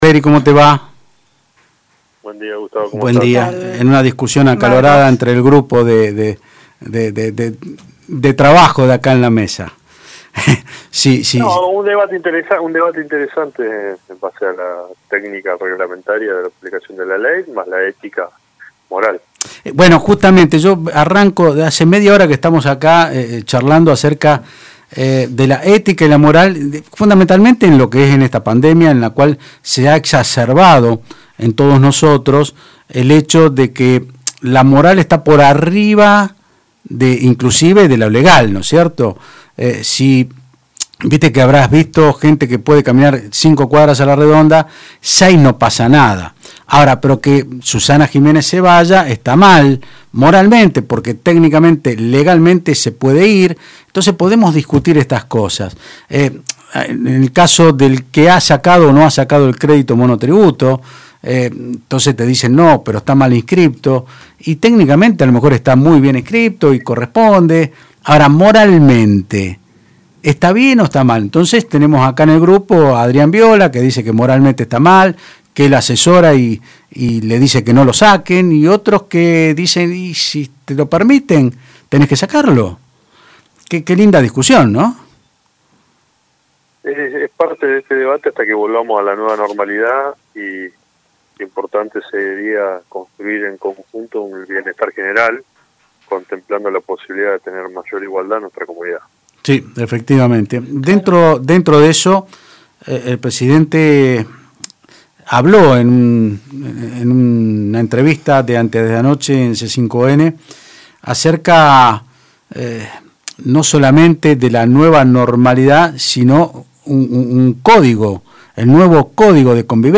El diputado nacional del Frente de Todos Marcos Cleri anunció en Otros Ámbitos (Del Plata Rosario 93.5) que se presentará como querellante en la denuncia penal que hizo este martes la titular de la Agencia Federal de Inteligencia (AFI), Cristina Caamaño, por presunto espionaje ilegal a políticos, periodistas, dirigentes y policías durante el gobierno de Cambiemos.